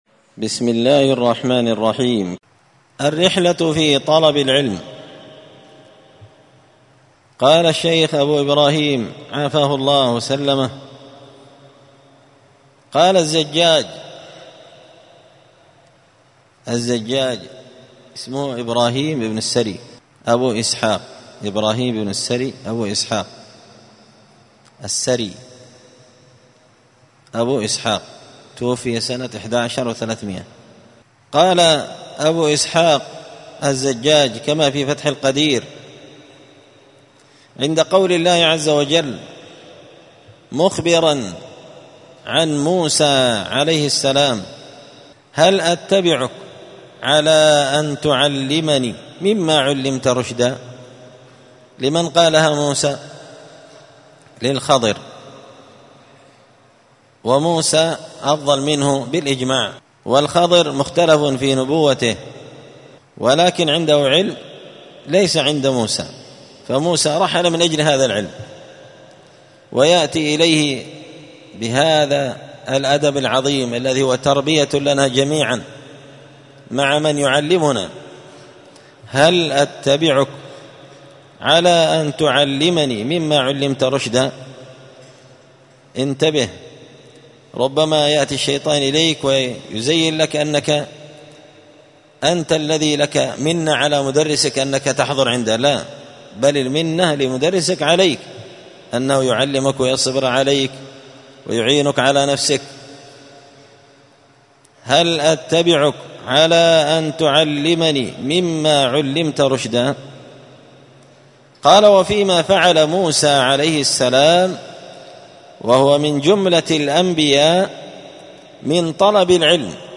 دار الحديث السلفية بمسجد الفرقان